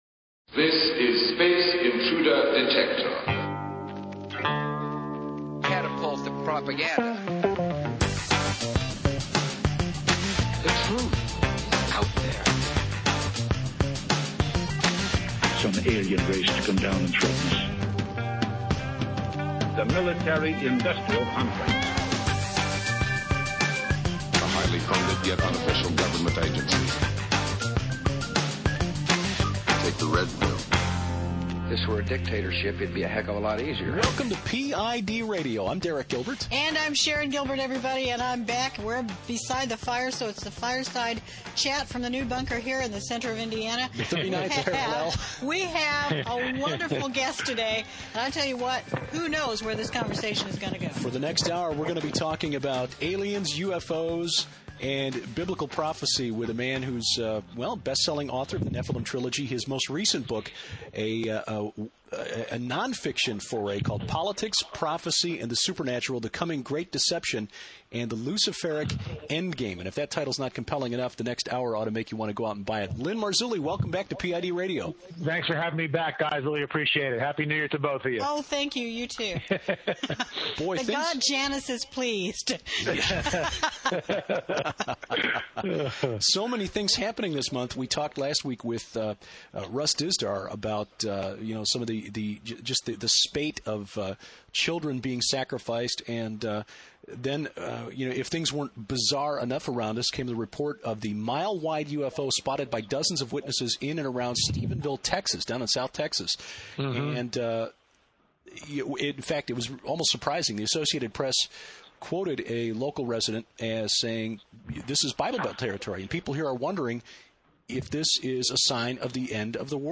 Listen Now--> 60 Minute interview Interview found here http